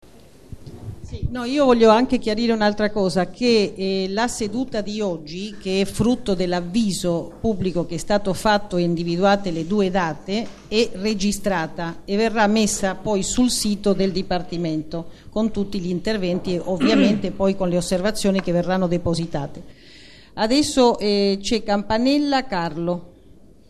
Registrazione integrale dell'incontro svoltosi il 15 luglio 2014 presso la Sala Rossa del Municipio VII, in Piazza di Cinecittà, 11
17-fantino Ana Susana Fantino, Presidente del VII Municipio